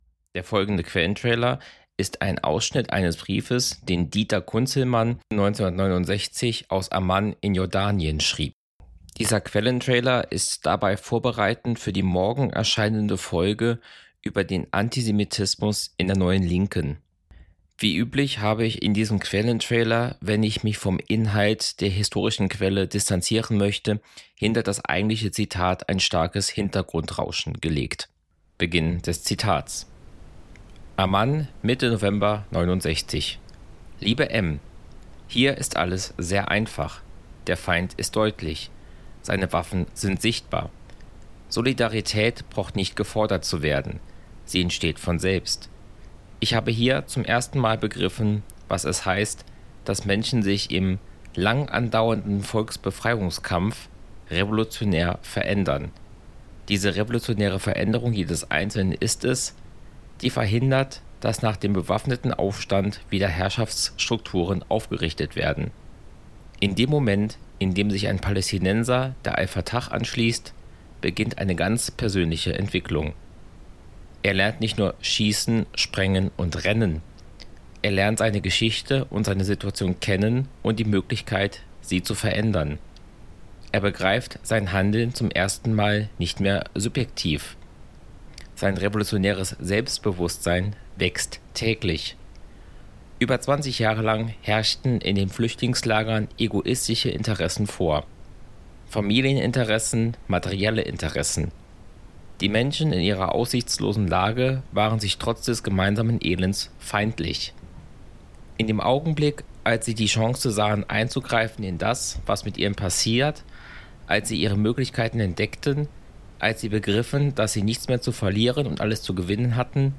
Das Hintergrundrauschen ist absichtlich eingefügt und stellt meine Distanzierung vom Inhalt des historischen Quellentextes dar.